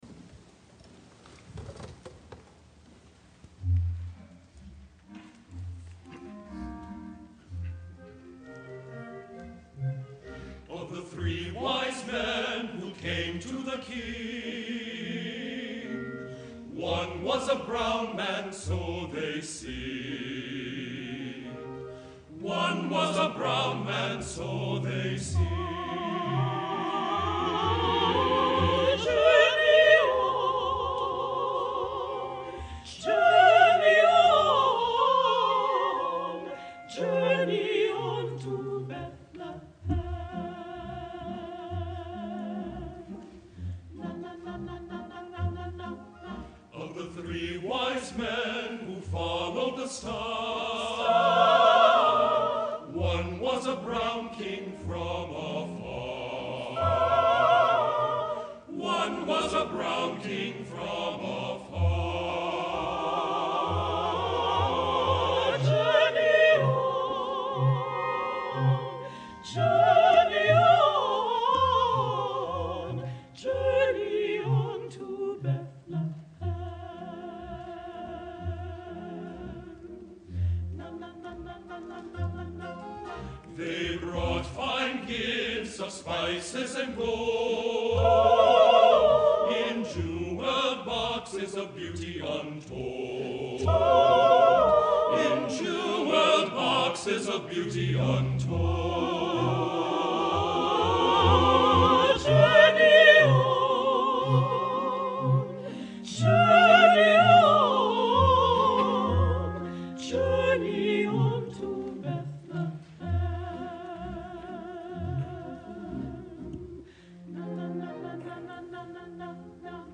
SATB and organ